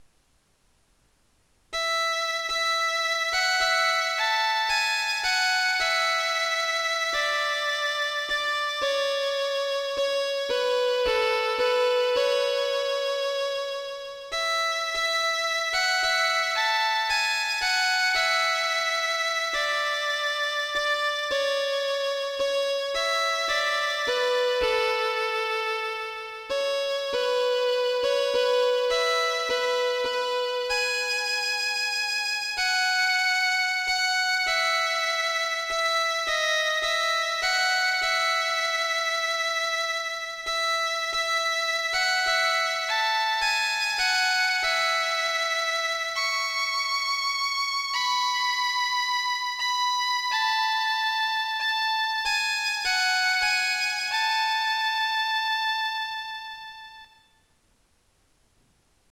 演奏2 mp3